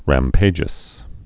(răm-pājəs)